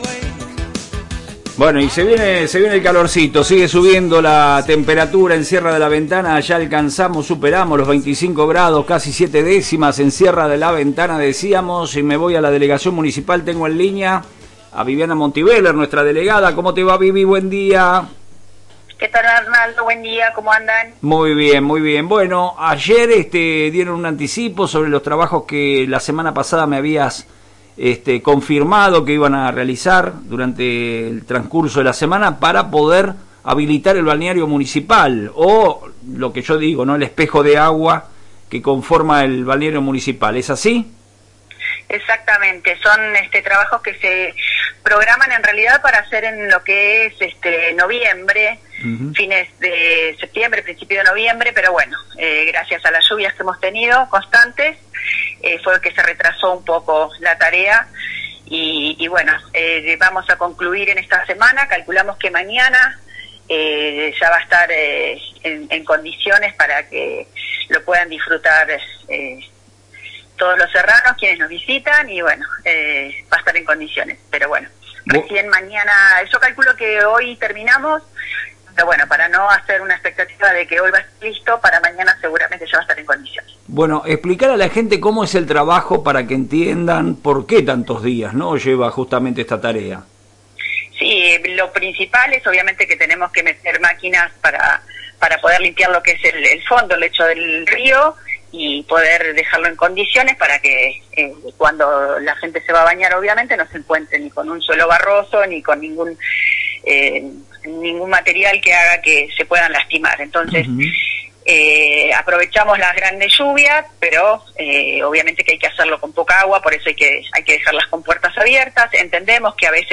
La delegada Viviana Montibeller confirmó en FM Reflejos que, a pesar de las demoras por las lluvias, esta semana finalizarán las tareas de acondicionamiento del dique con maquinaria pesada y asegurará que el balneario esté «en todo su esplendor» para la apertura oficial de temporada del 6, 7 y 8 de diciembre, con servicio de guardavidas y piletas municipales listas.